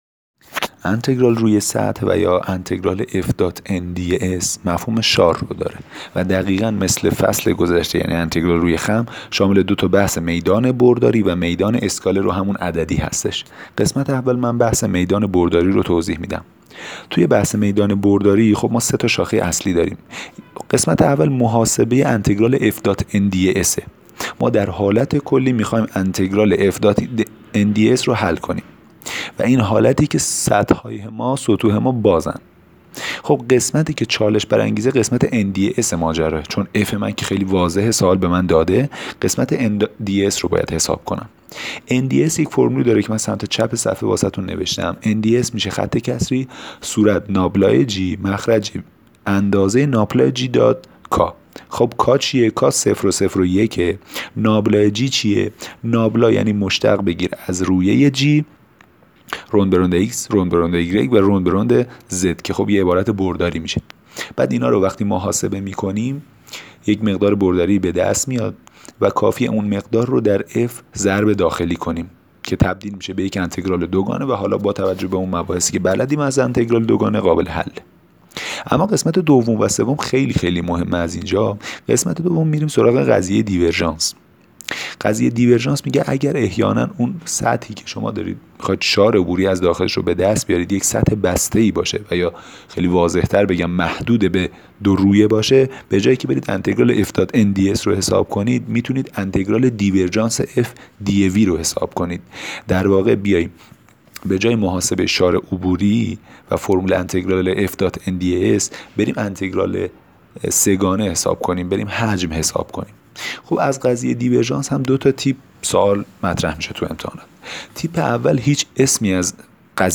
فایل صوتی تدریس انتگرال روی سطح